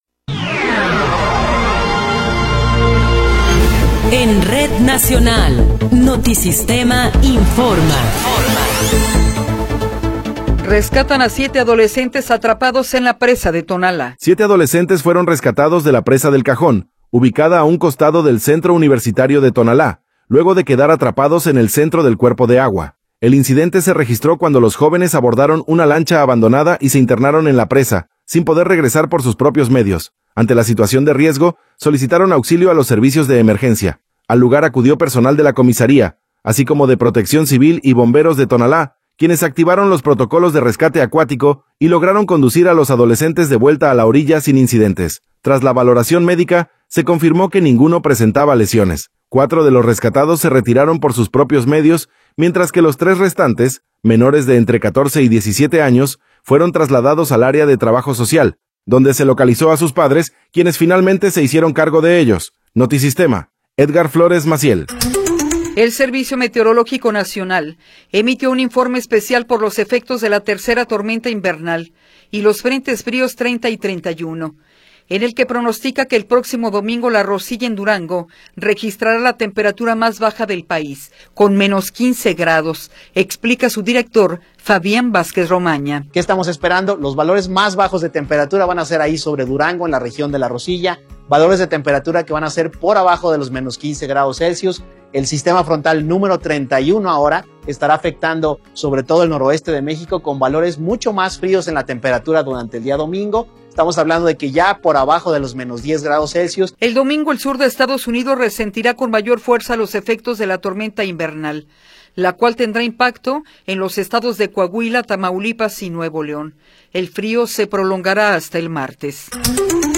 Noticiero 12 hrs. – 23 de Enero de 2026
Resumen informativo Notisistema, la mejor y más completa información cada hora en la hora.